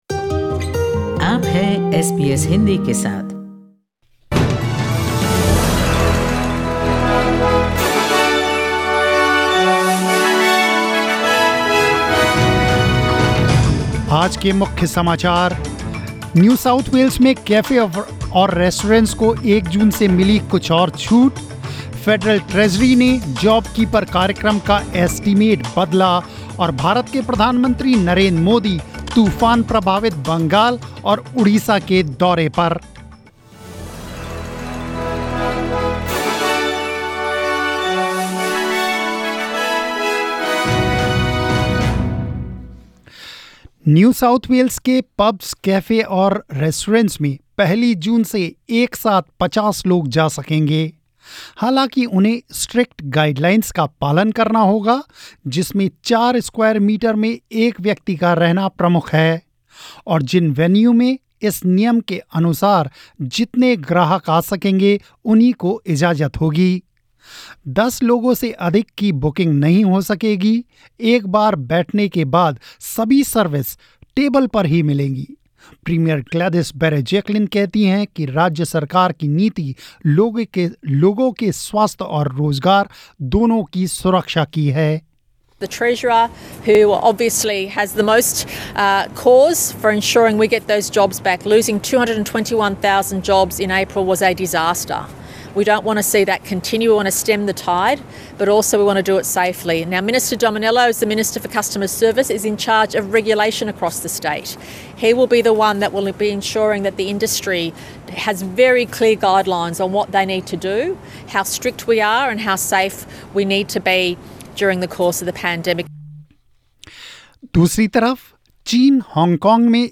News in Hindi 22 May 2020